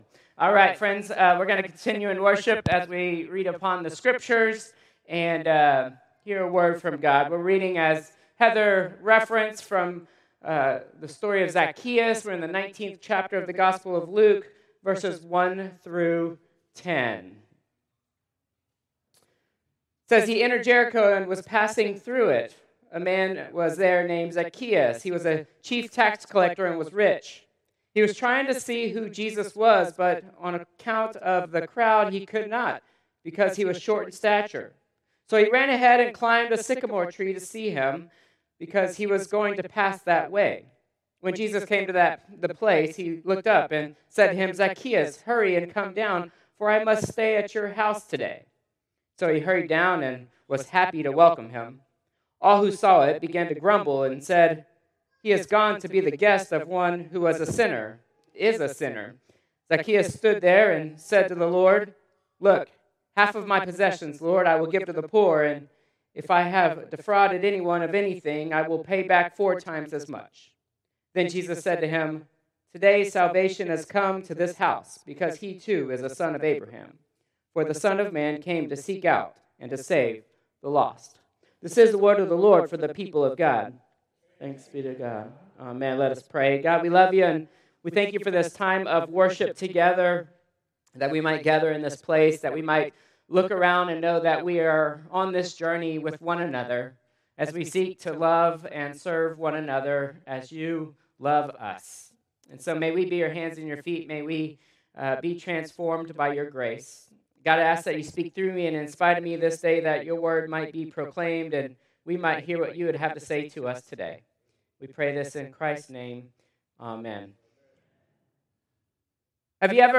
Combined Service 10/19/2025